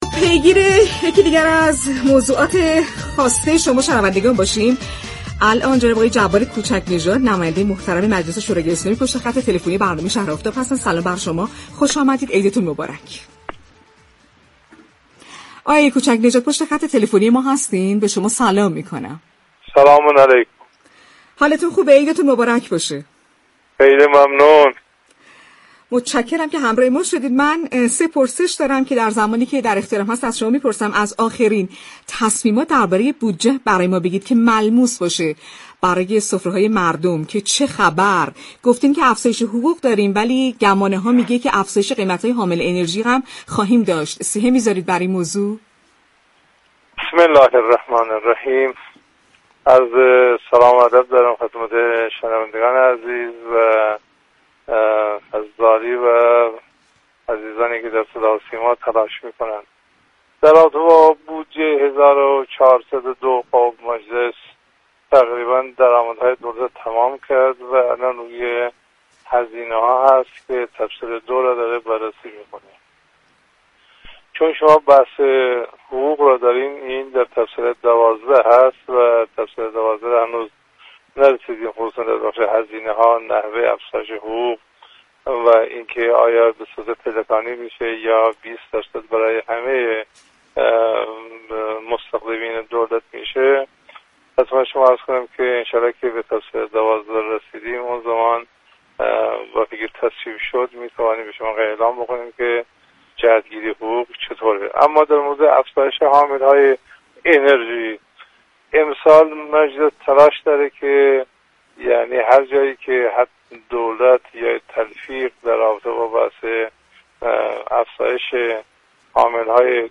به گزارش پایگاه اطلاع رسانی رادیو تهران، جبار كوچكی نژاد عضو كمیسیون برنامه و بودجه و محاسبات مجلس شورای اسلامی در گفت و گو با «شهر آفتاب» درخصوص آخرین اخبار بودجه سال 1402 اظهار داشت: منابع درآمدهای دولت در بودجه سال 1402 در مجلس بررسی شد و هم اكنون در حال آغاز بررسی هزینه‌های بودجه است.